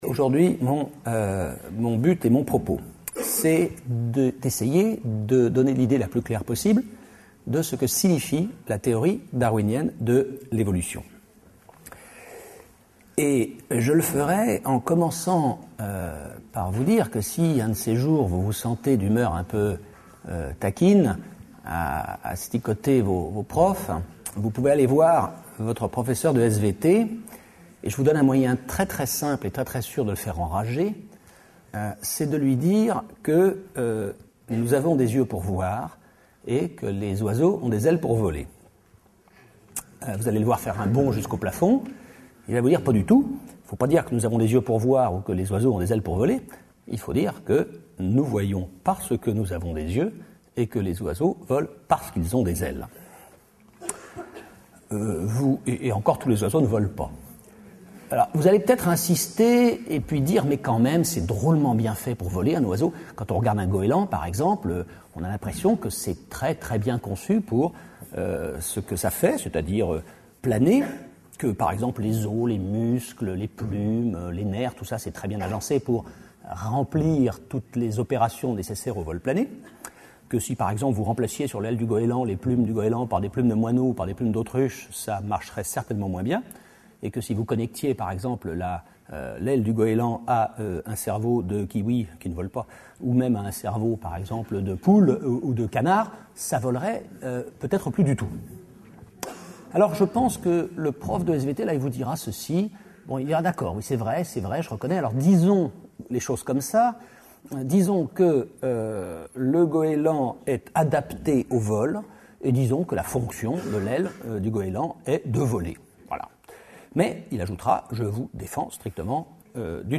Une conférence de l'UTLS au Lycée La théorie de l'évolution